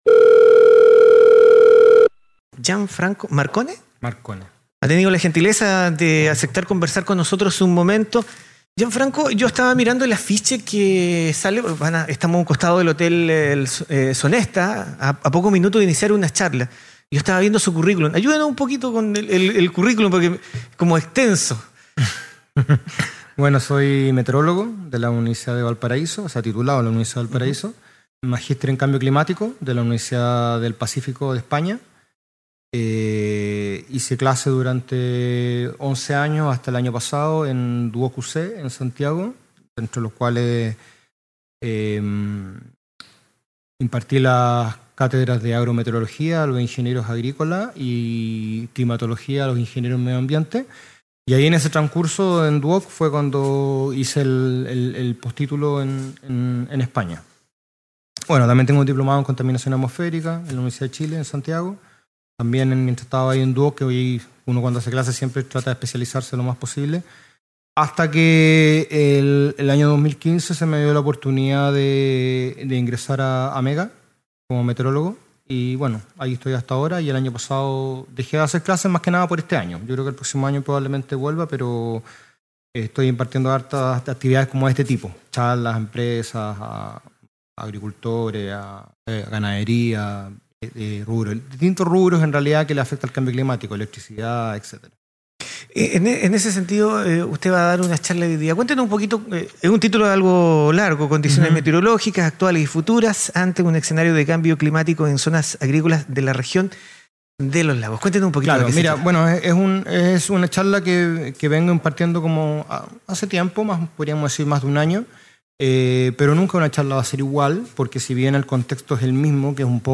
es la charla que realizó en uno de los salones del Hotel Sonesta